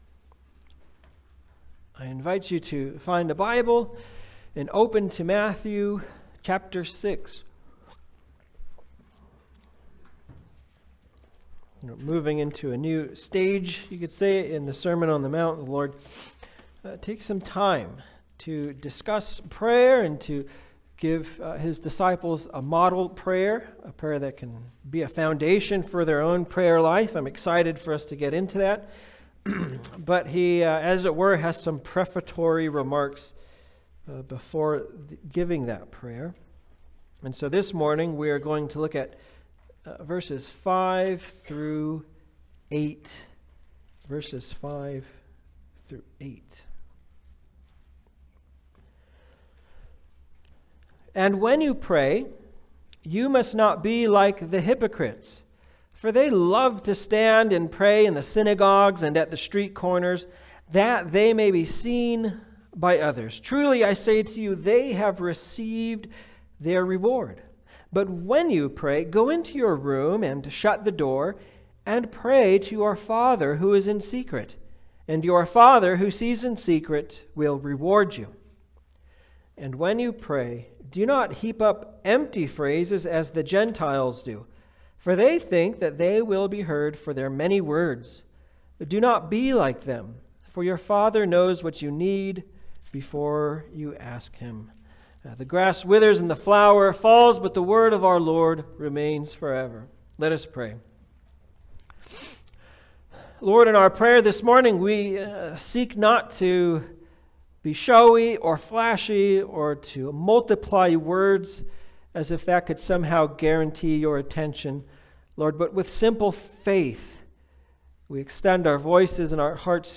Passage: Matthew 6:5-8 Service Type: Sunday Service